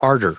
added pronounciation and merriam webster audio
1956_ardor.ogg